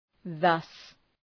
Προφορά
{ðʌs}